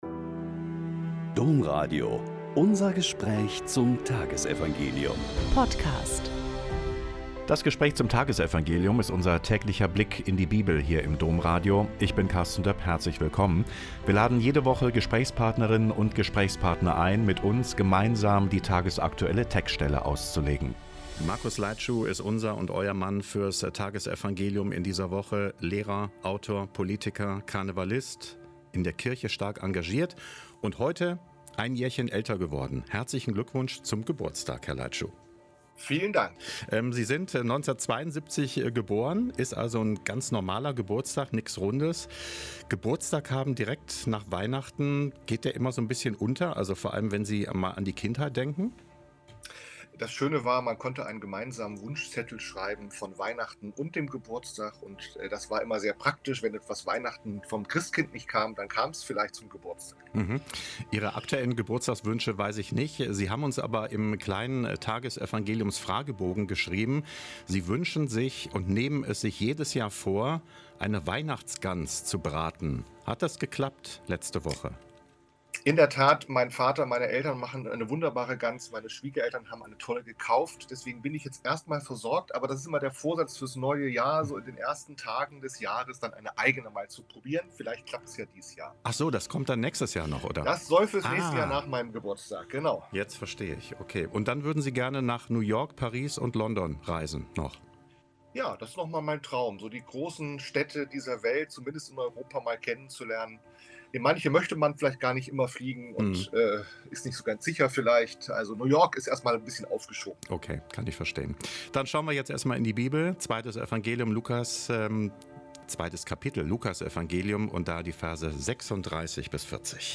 Lk 2,36-40 - Gespräch